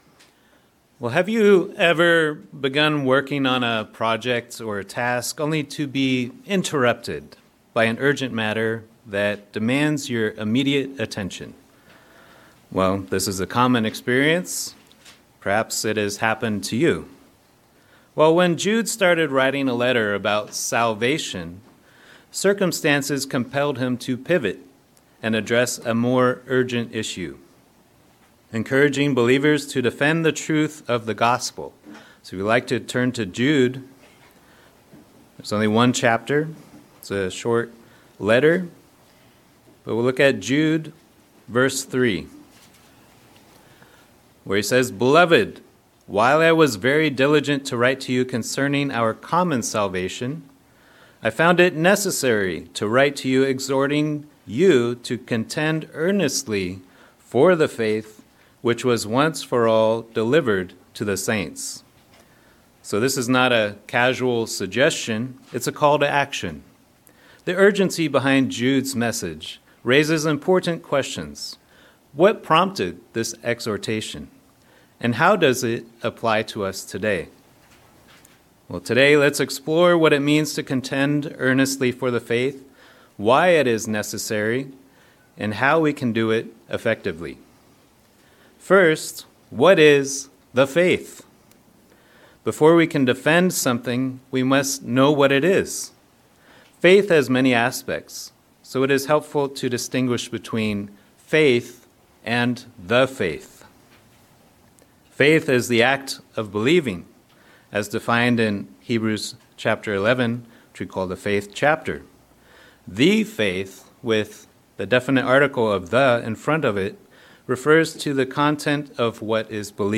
Sermons
Given in Northern Virginia